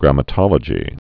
(grămə-tŏlə-jē)